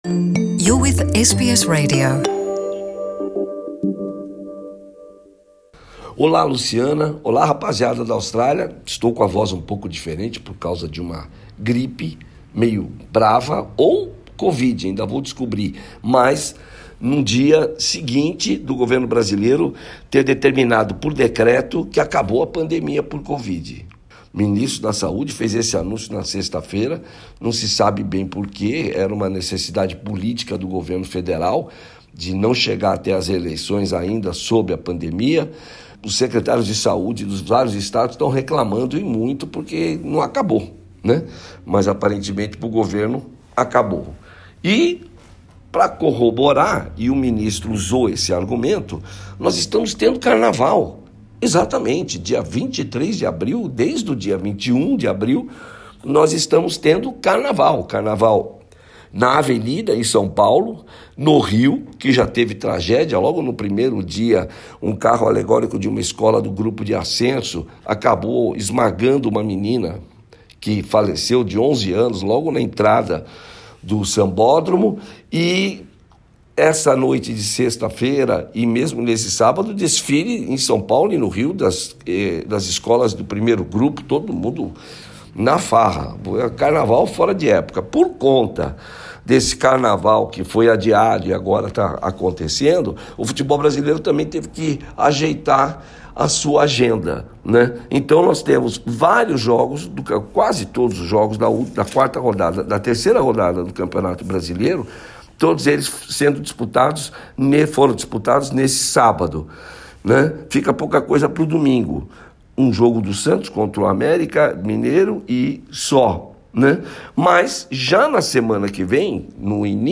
Ainda neste boletim esportivo, na Argentina, uma confusão envolve a partida entre Argentina e Brasil marcada para Melbourne em junho.